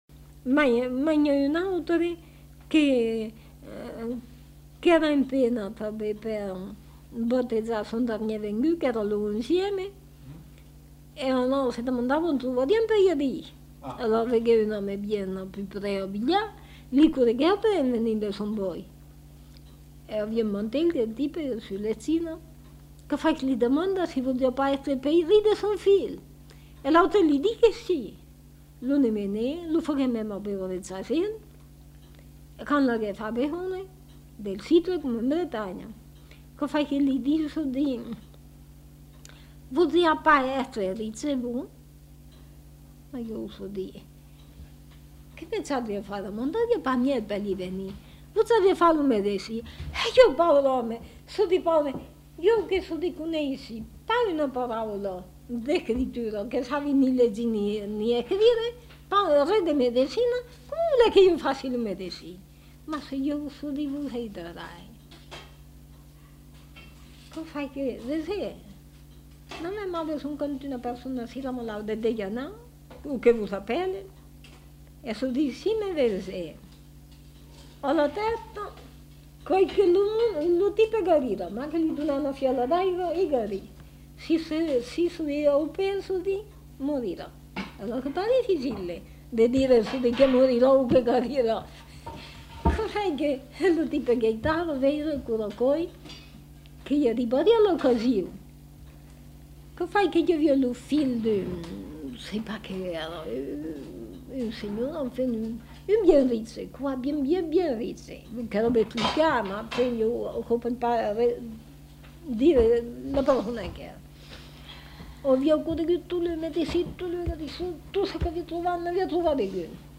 Aire culturelle : Périgord
Genre : conte-légende-récit
Effectif : 1
Type de voix : voix de femme
Production du son : parlé